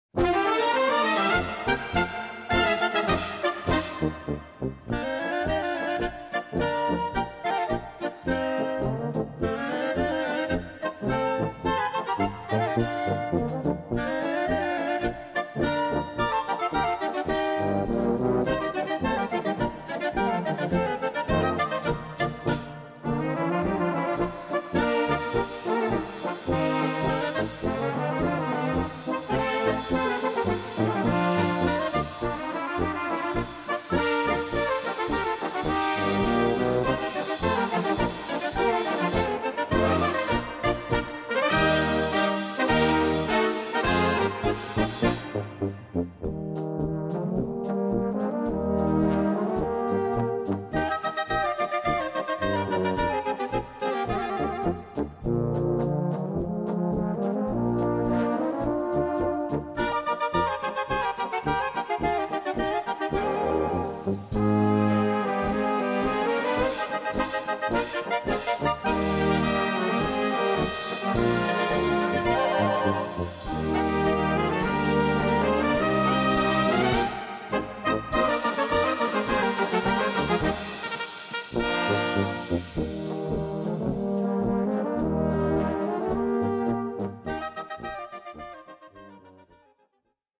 Chant et Piano